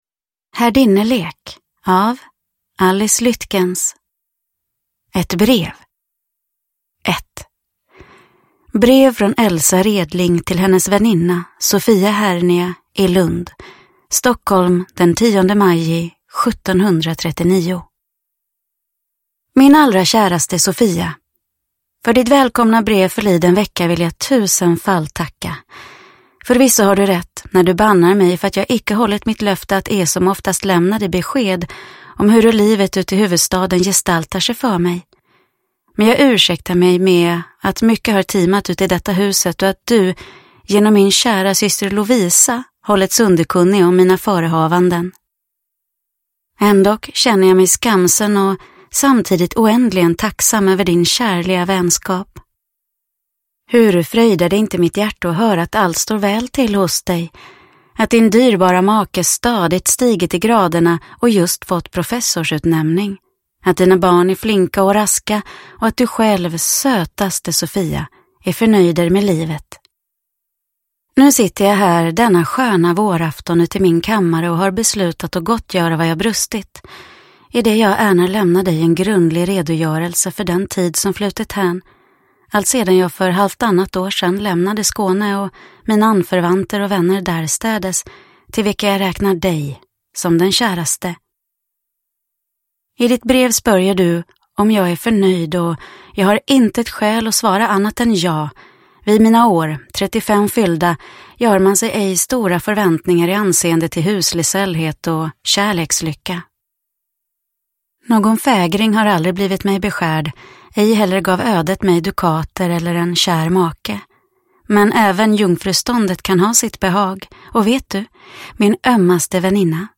Herdinnelek – Ljudbok – Laddas ner